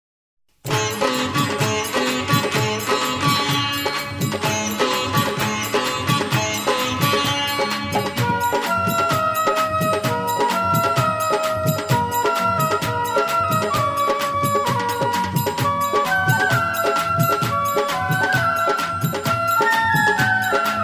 Hanuman bhajan